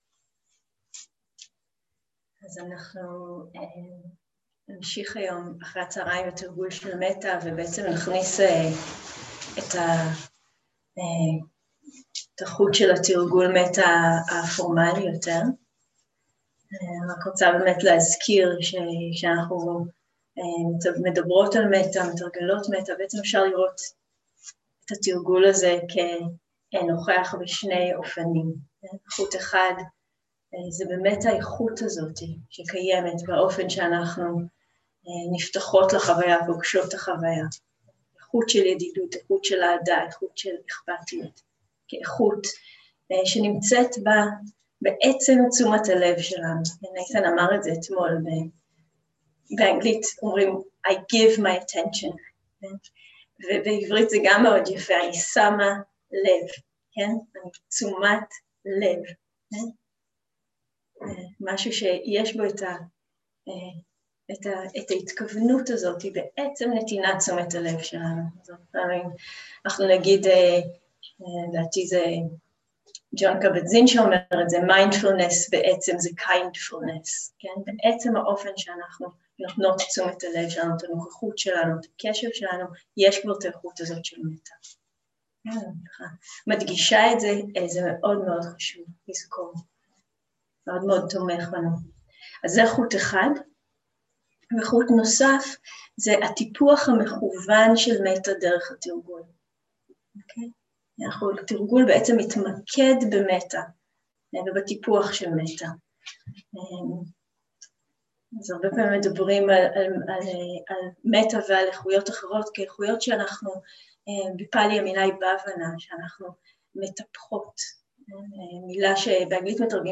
מדיטציה מונחית - מטא לדמות הקלה, לעצמנו ולעולם